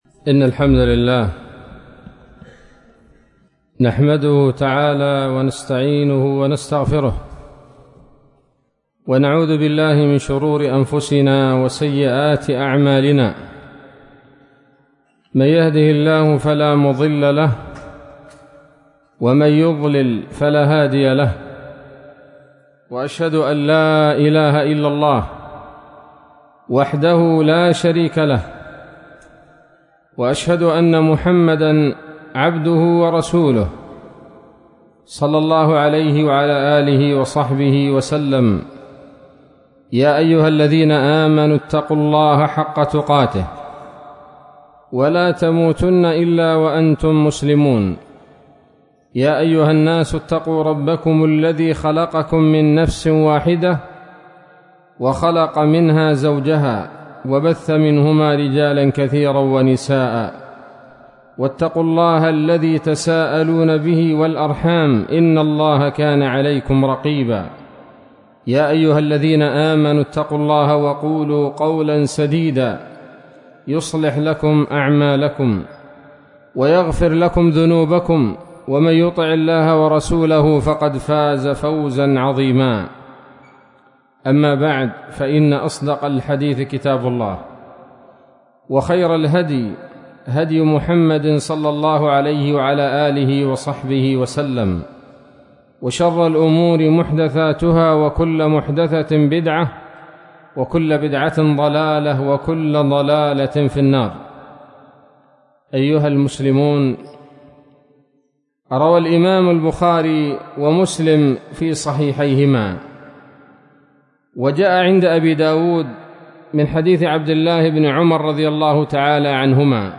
محاضرة قيمة بعنوان: (( احذروا المخدرات )) ليلة السبت 19 ذي القعدة 1443هـ، بمسجد الريان - منطقة جعولة - عدن - اليمن